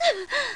SFXv配音-女惨叫-轻-001音效下载
SFX音效